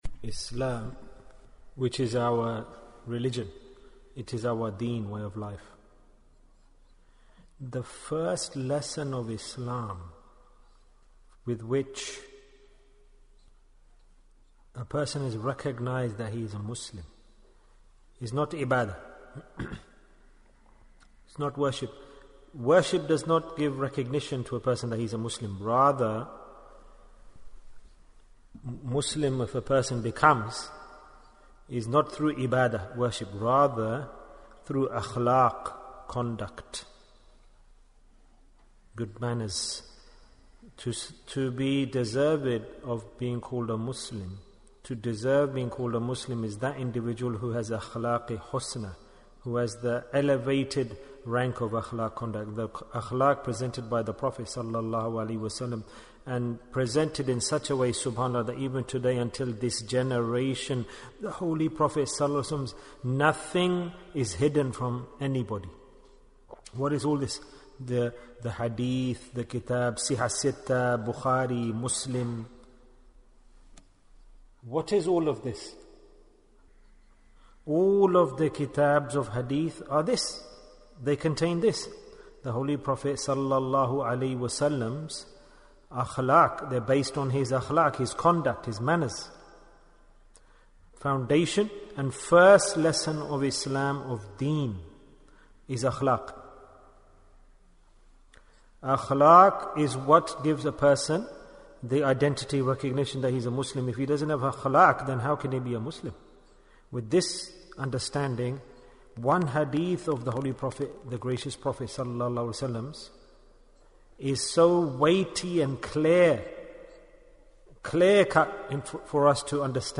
Who are Muslims? Bayan, 29 minutes17th December, 2020